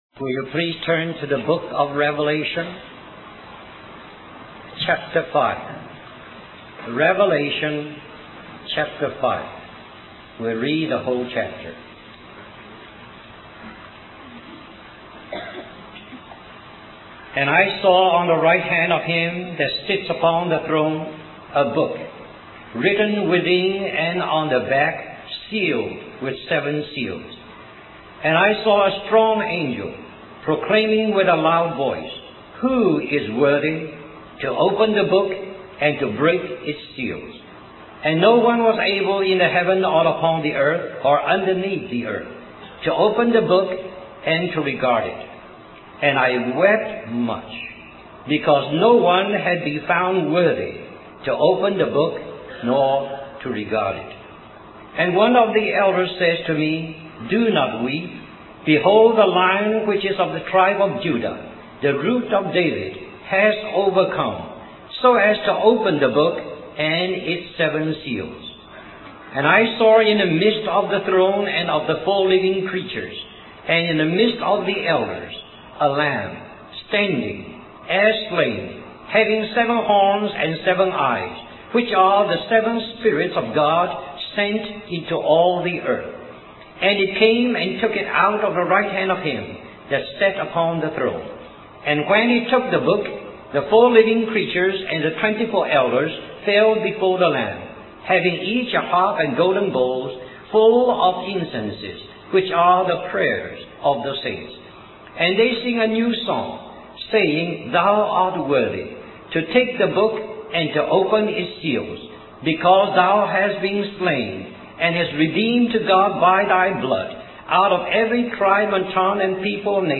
1988 Christian Family Conference Stream or download mp3 Summary During June